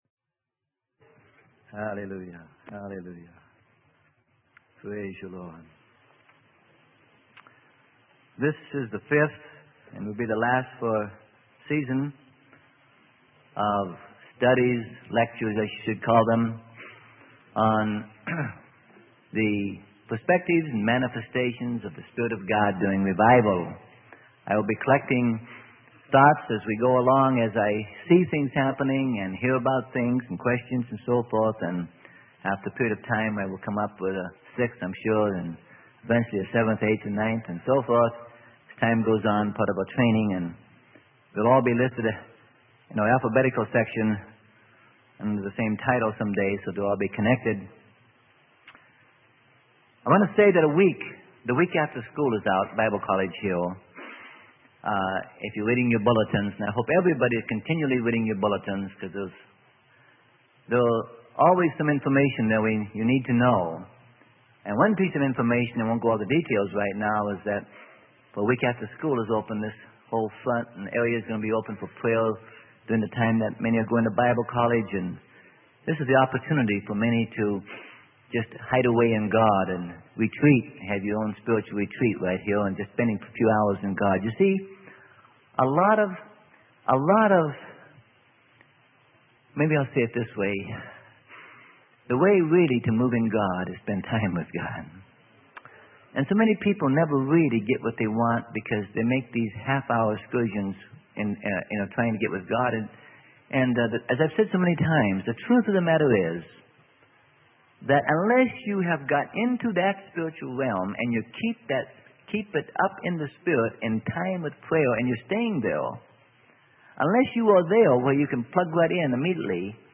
Sermon: The Balance in Manifestations of the Spirit - Part 6 - Freely Given Online Library